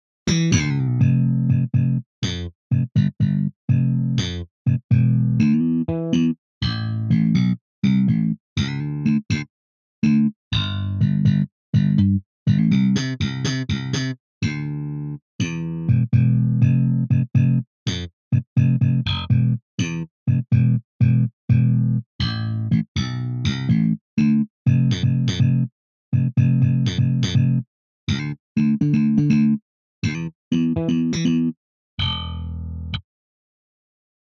Funky Picked